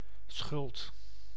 Ääntäminen
IPA: [sχ͡ʀø̝̆lˠt]